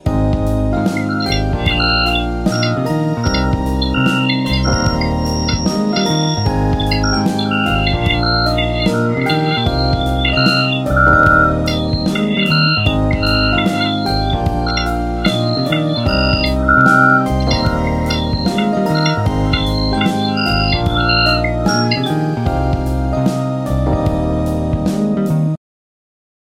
Description: Note: Volume warning My radio picked up some weird interference - I’m sure it’s aliens but nobody believes me!!!
Listening to the audio file shows nothing special but some squeak like sounds in the back. This clued me in to view the spectogram.
alien_transmission_audio.mp3